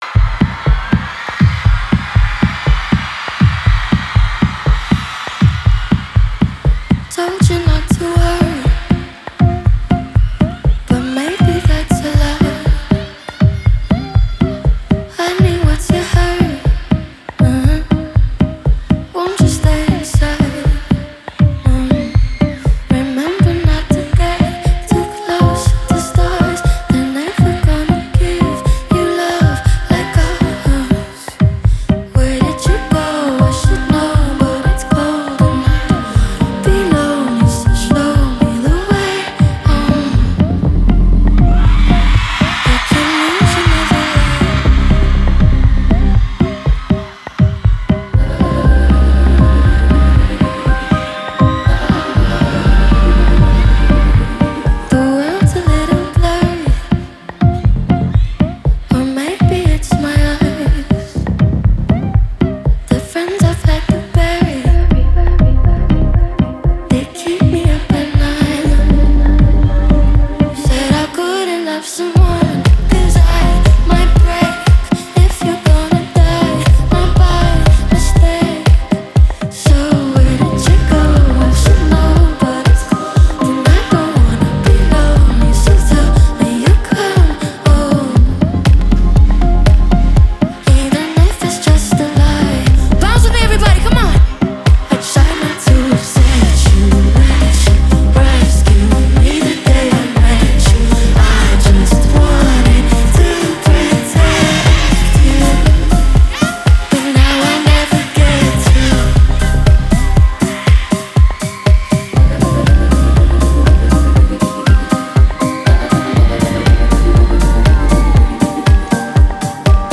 атмосферная и эмоциональная песня
выразительный вокал